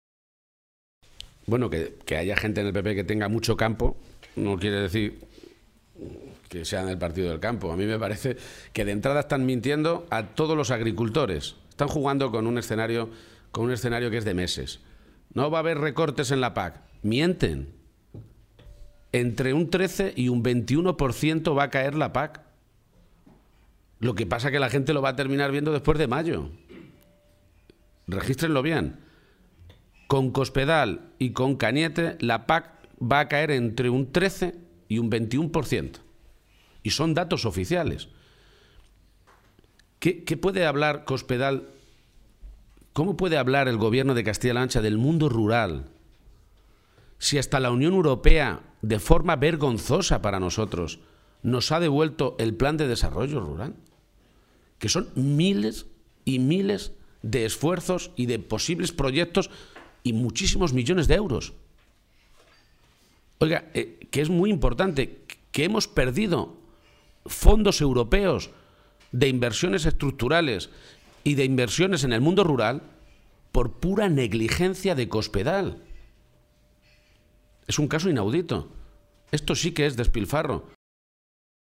Cortes de audio de la rueda de prensa
Audio García-Page desayuno informativo en Albacete-4